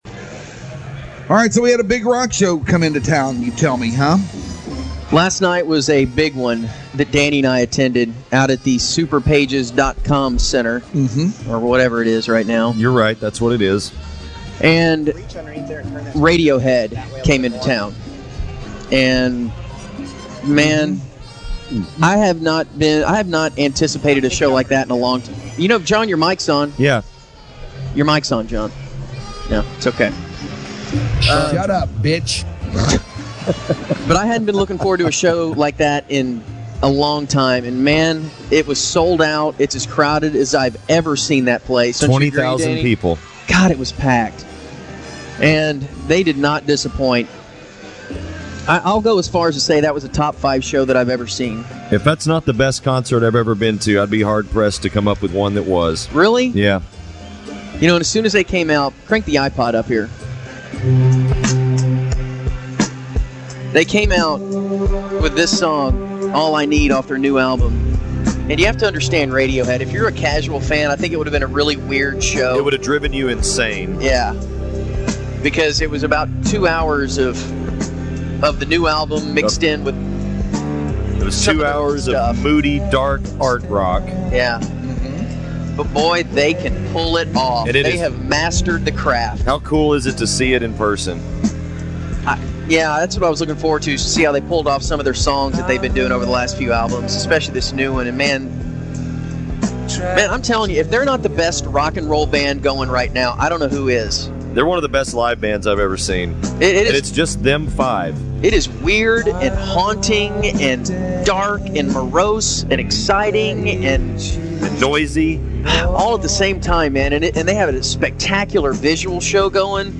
hardlineradiohead.mp3